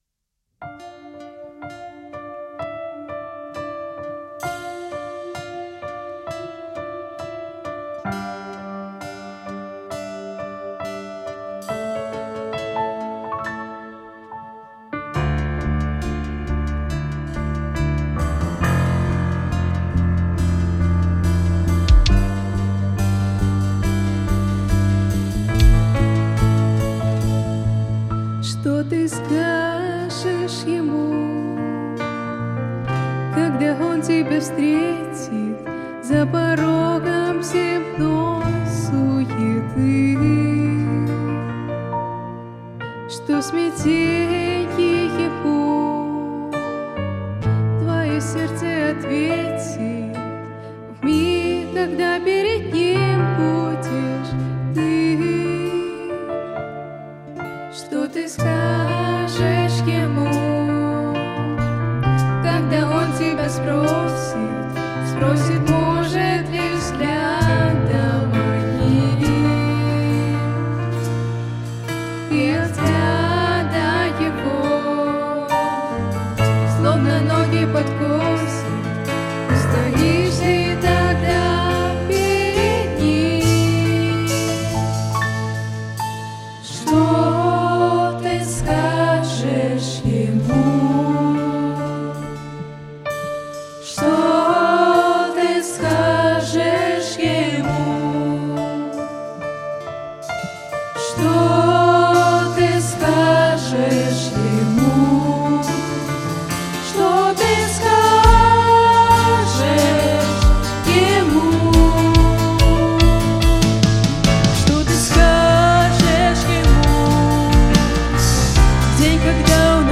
Богослужение (ЧТ) - 6 ноября 2025
Song